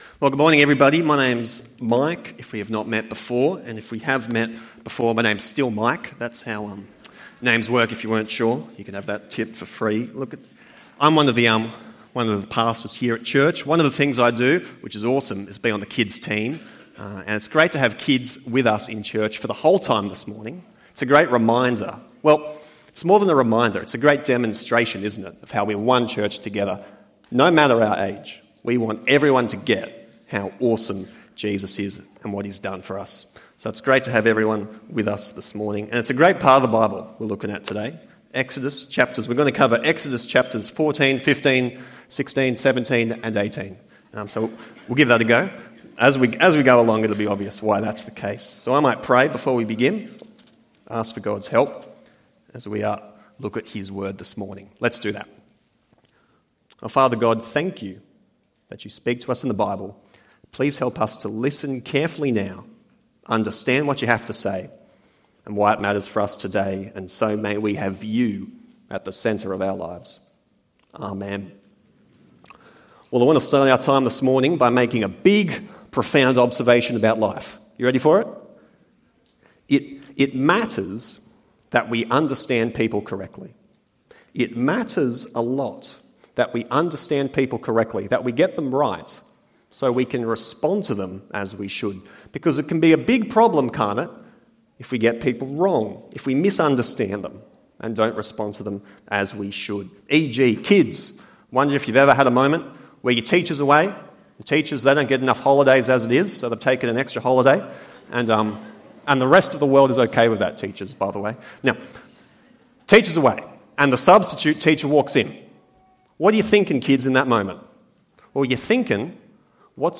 God deserves our praise (Praise and Grumbling) ~ EV Church Sermons Podcast